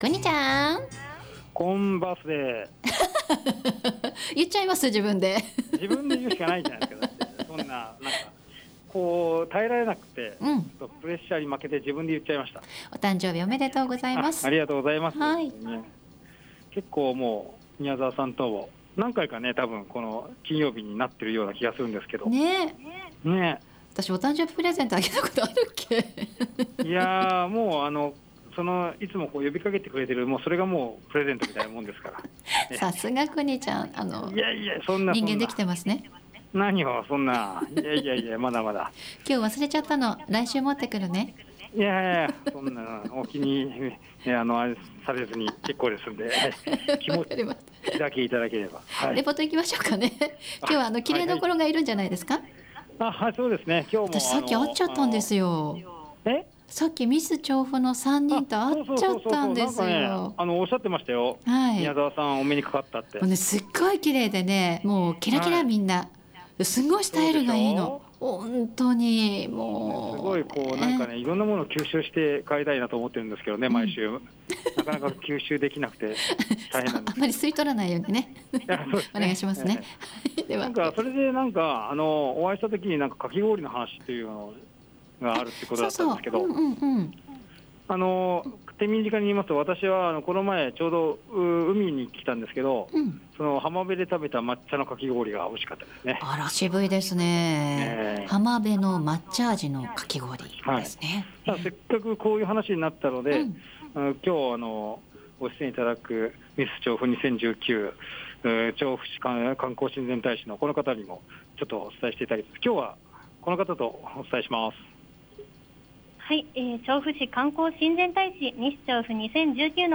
さて今週も商工会さんにお邪魔しています。